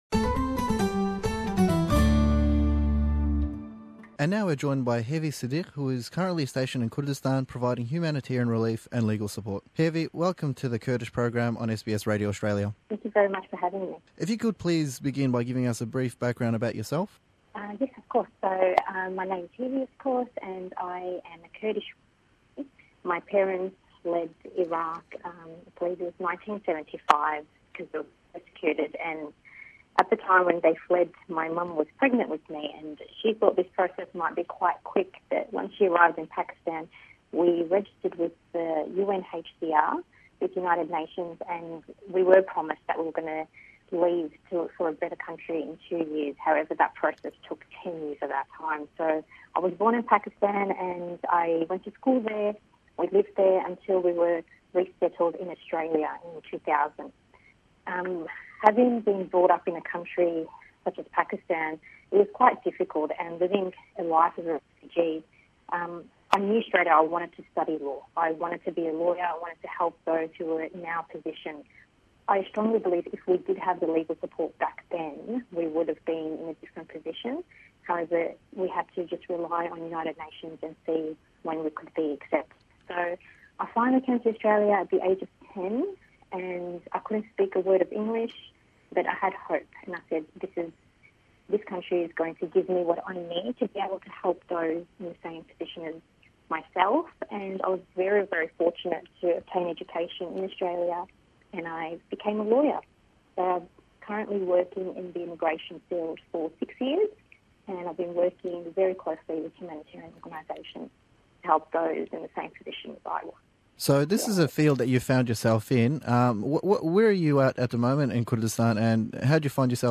Êsta lêdwanêk legel ew be Inglîzî sebaret be îsh û karî le wê.